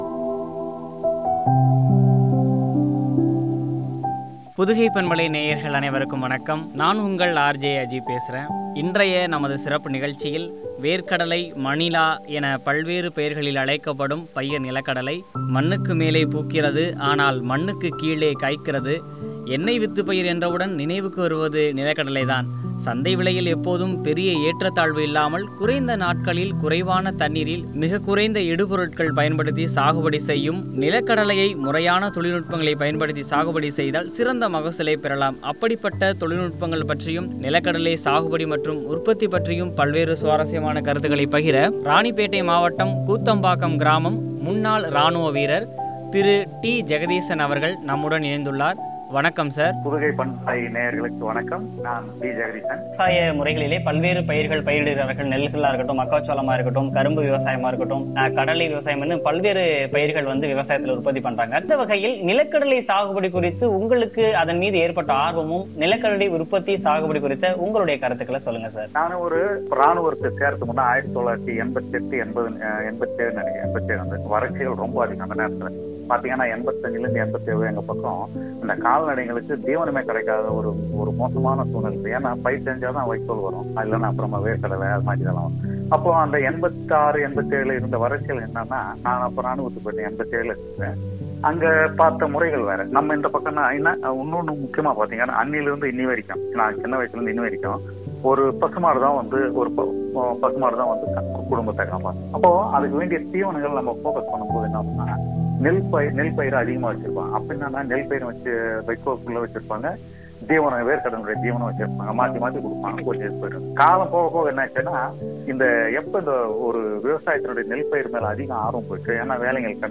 பற்றி வழங்கிய உரையாடல்.